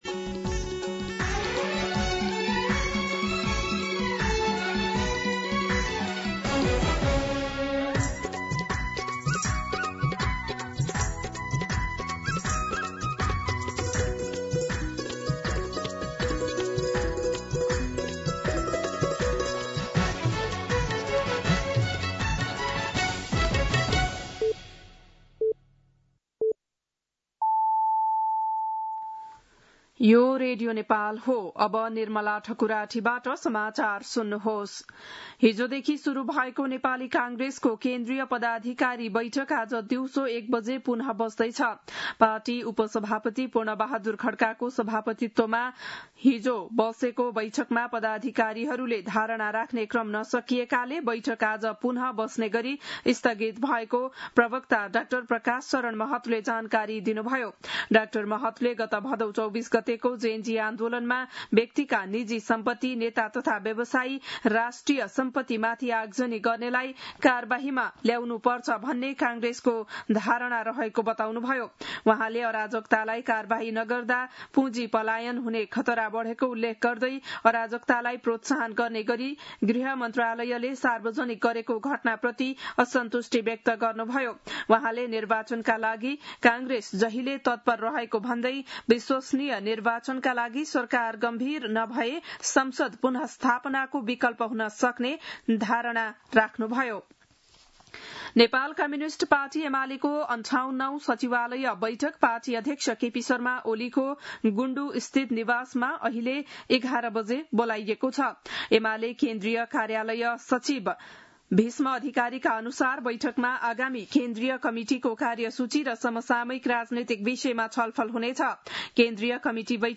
बिहान ११ बजेको नेपाली समाचार : २४ असोज , २०८२